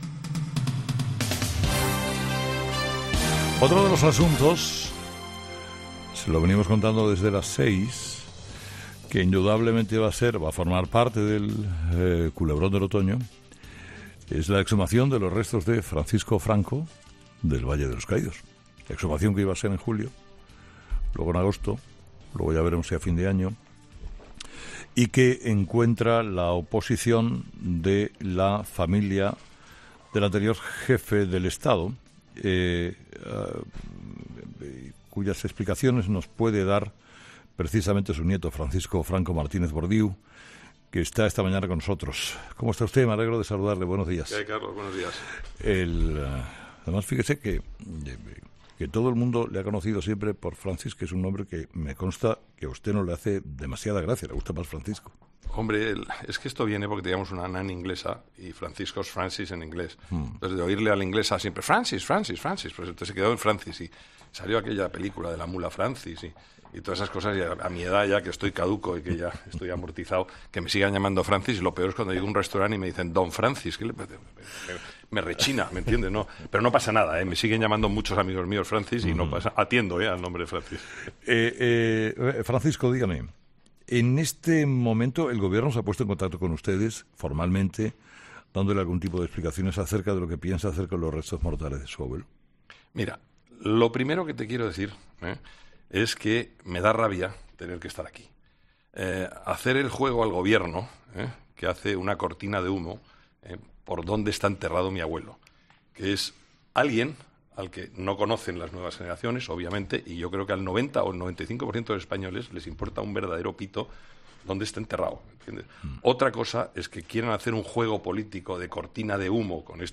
Herrera en COPE Entrevista